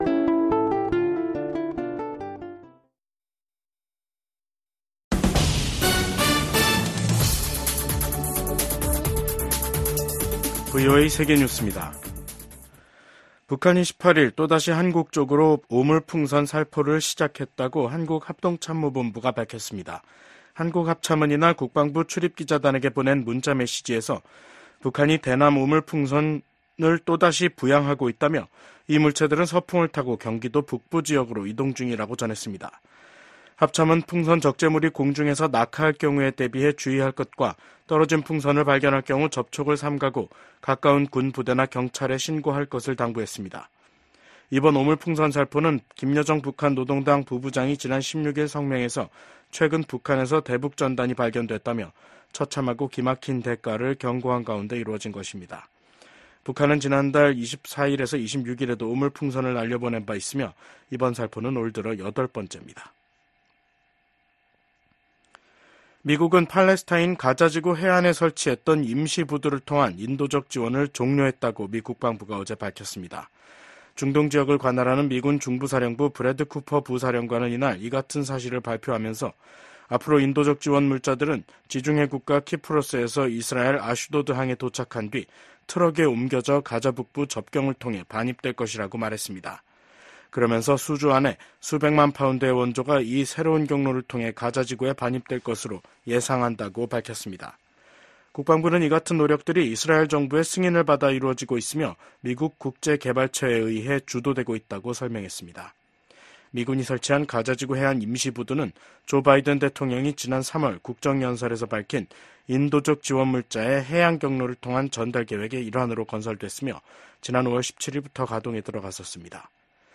VOA 한국어 간판 뉴스 프로그램 '뉴스 투데이', 2024년 7월 18일 2부 방송입니다. 미 중앙정보국(CIA) 출신의 대북 전문가가 미 연방검찰에 기소됐습니다. 북한이 신종 코로나바이러스 감염증 사태가 마무리되면서 무역 봉쇄를 풀자 달러 환율이 고공행진을 지속하고 있습니다. 최근 북한을 방문한 유엔 식량농업기구 수장이 북한이 농업 발전과 식량 안보에서 큰 성과를 냈다고 주장했습니다.